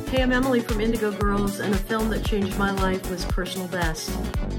(captured from the vimeo livestream)
06. talking with the crowd (emily saliers) (0:04)